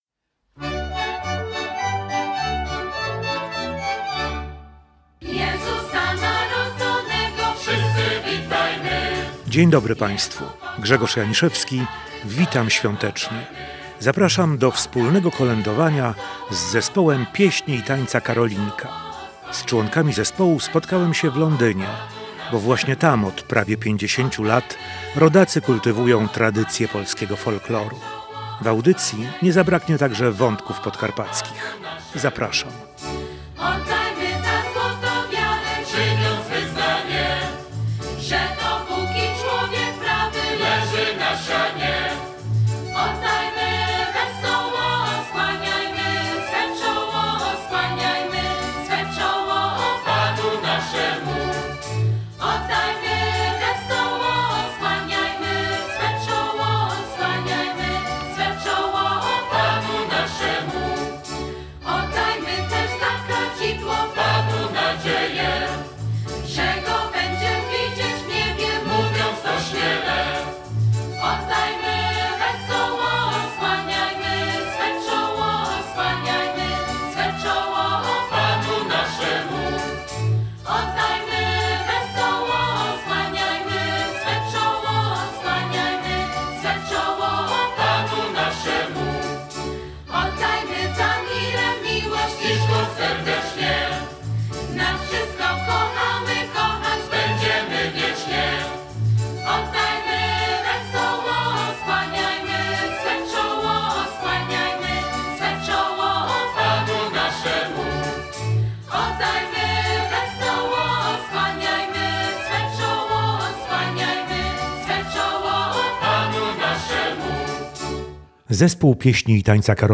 Relacje reporterskie • Zespół Pieśni i Tańca „Karolinka” z południowego Londynu to jeden z najbardziej renomowanych polonijnych zespołów
Koledy-Zespolu-Piesni-i-Tanca-Karolinka-z-Londynu.mp3